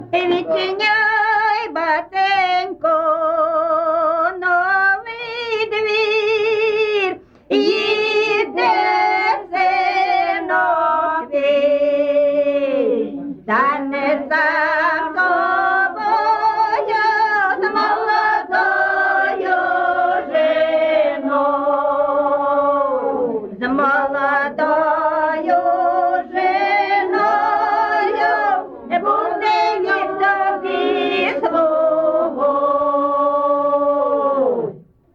ЖанрВесільні
Місце записус. Шарівка, Валківський район, Харківська обл., Україна, Слобожанщина